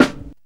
VINYL 2.wav